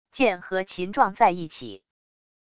剑和琴撞在一起wav音频